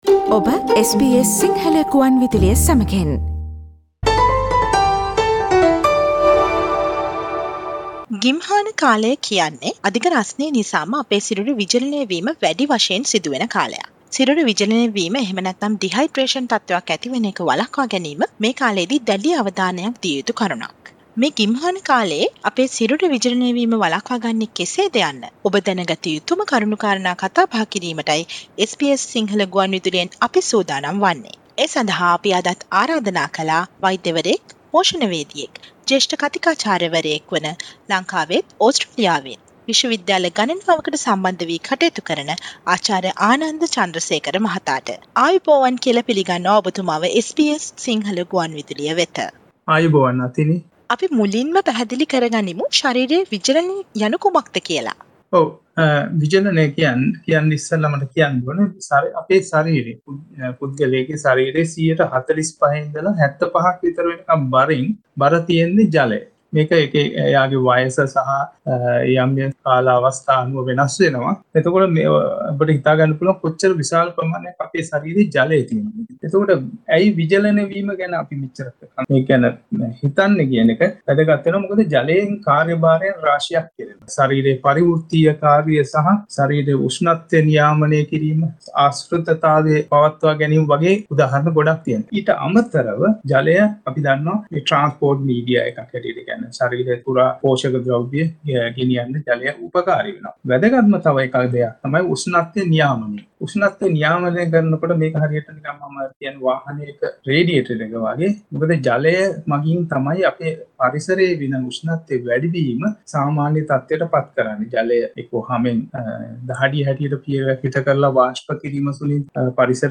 SBS සිංහල වැඩසටහන